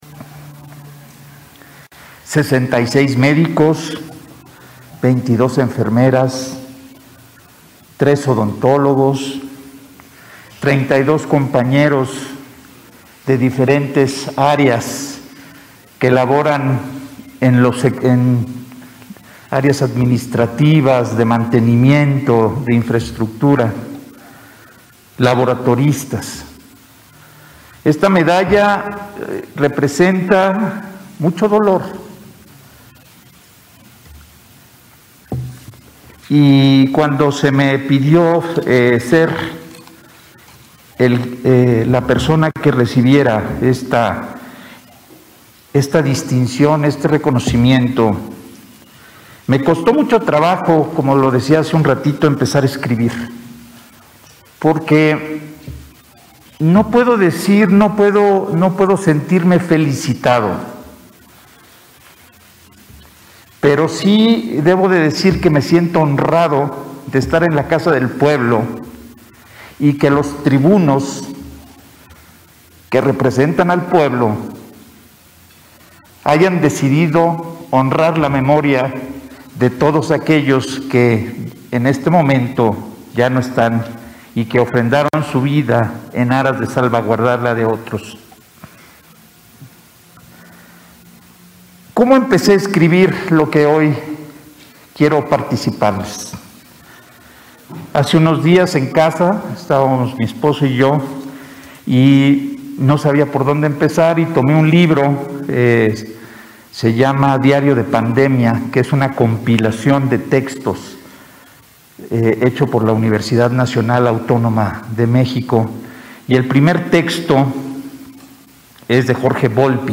Pachuca, Hgo., 25 de agosto del 2021.- Este miércoles 25 de agosto de 2021, se llevó a cabo la Sesión Solemne número 10 de la LXIV Legislatura del Congreso del Estado de Hidalgo, en la cual se entregó la Presea “Don Miguel Hidalgo y Costilla” al personal médico fallecido en el desempeño de su trabajo por causa del COVID-19.
Efrain-Benitez-Mensaje_1.mp3